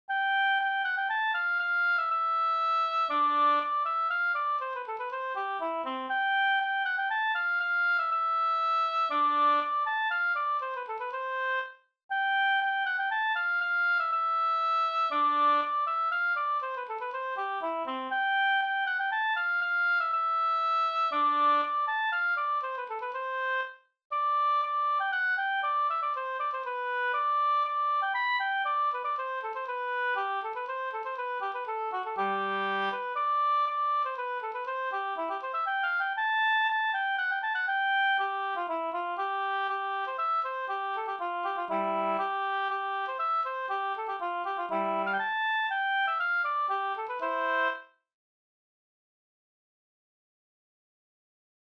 66 Soluppgång över Dellen, brudpolska efter "From-Olle", Olof Jonsson From, Järvsö, Hälsingland,
polska Soluppgang över Dellen e From-Olle.mp3